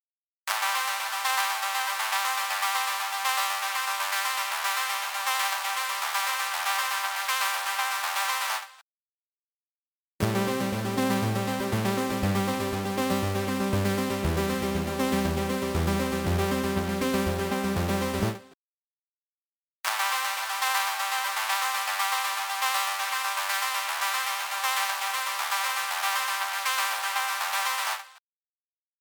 EQ65 | Synth | Preset: So Far Away